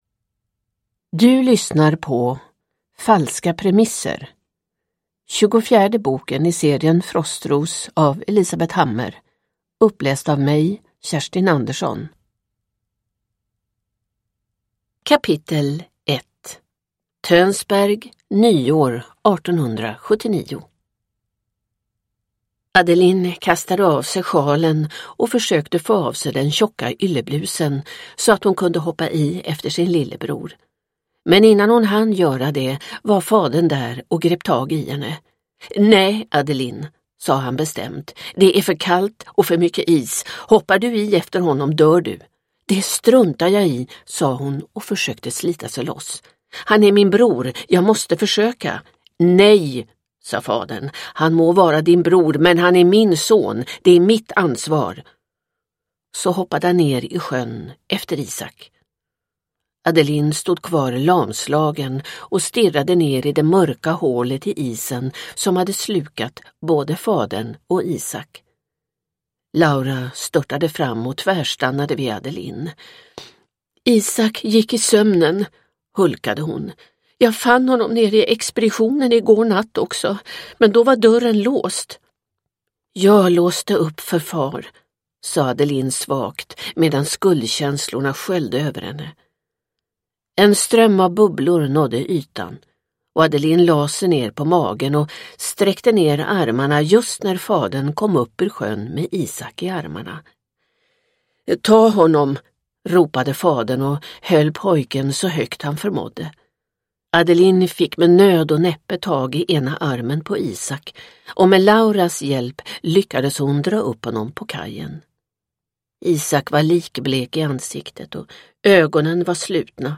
Falska premisser – Ljudbok